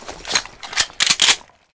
Wpn_lightmachgun_reload.ogg